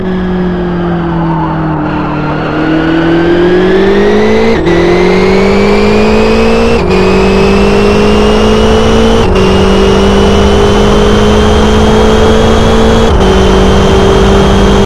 This is the XRR with my custom engine clip and transmission whine. The transmission whine is a work in progress but it's comming along.
XRR_newsounds.mp3 - 237.6 KB - 544 views